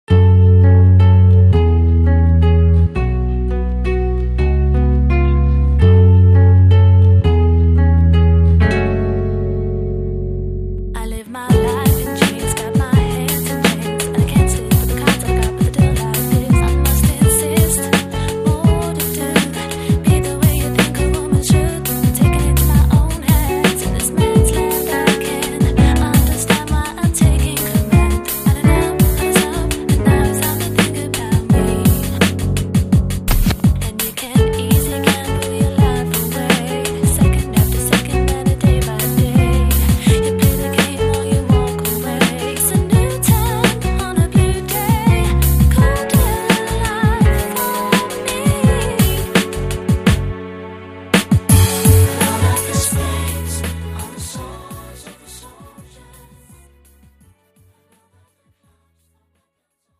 (팝송)